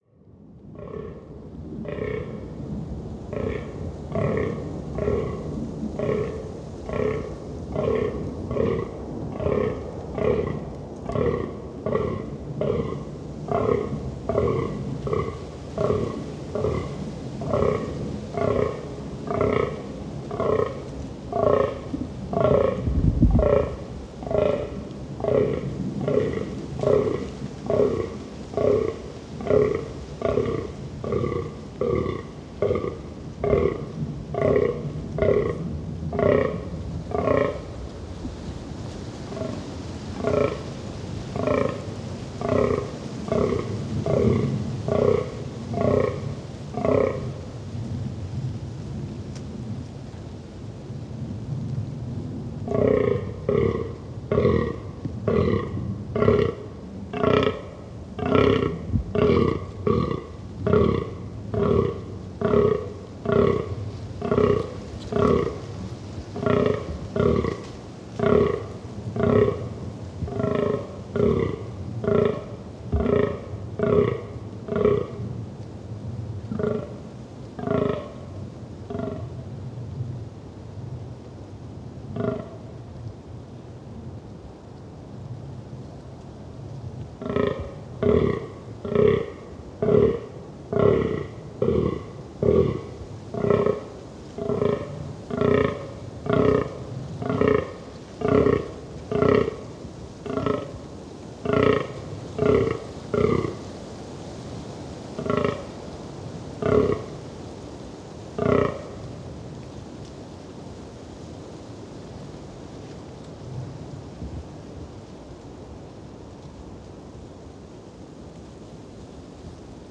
Лань издает такой звук в дикой природе Нидерланды